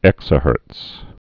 (ĕksə-hûrts)